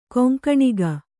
♪ koŋkaṇiga